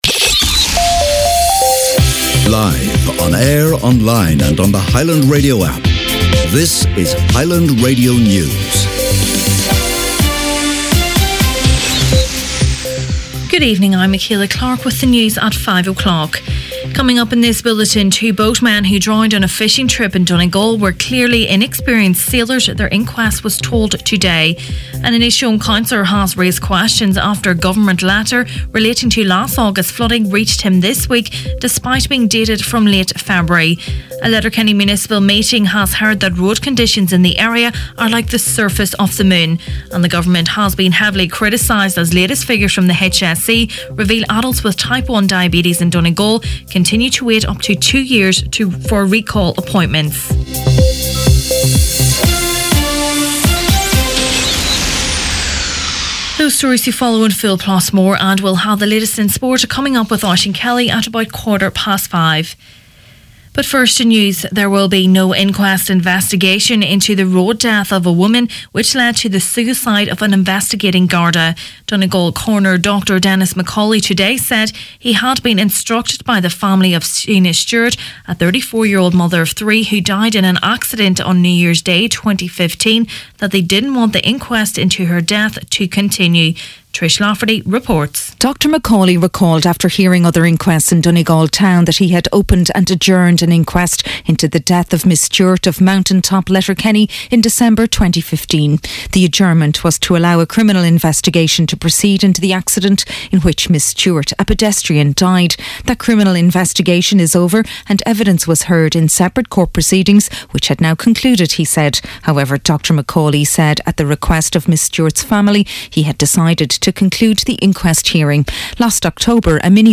Main Evening News, Sport and Obituaries Wednesday 11th April